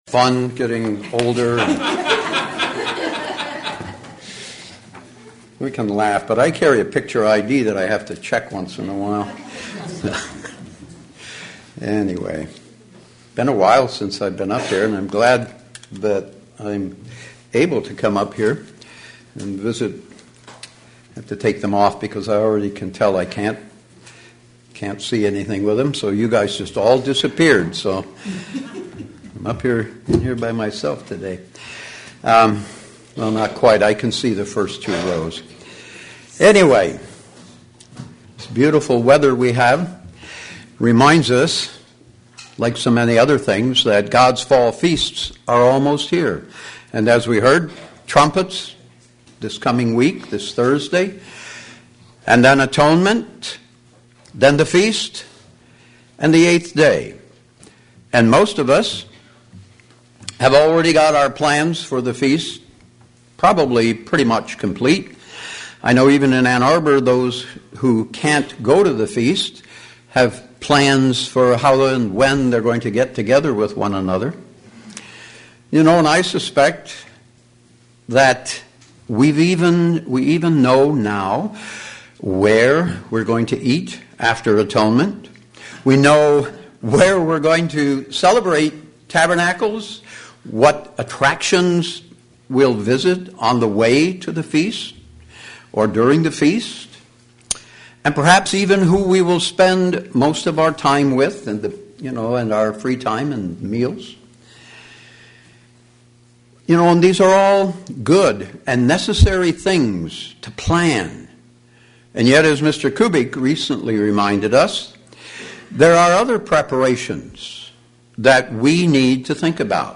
In which way are we to fear God? sermon Studying the bible?